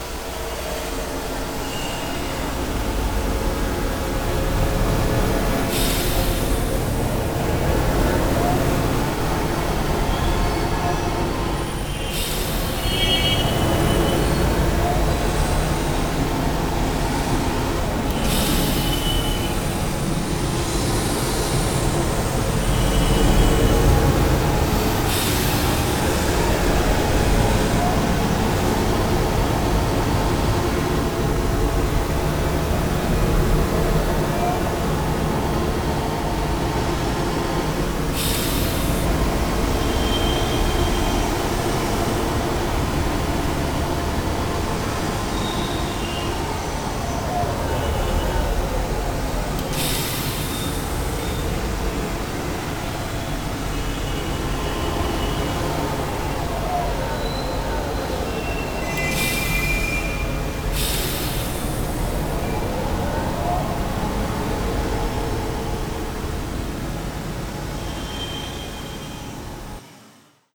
CitySoundTest2.wav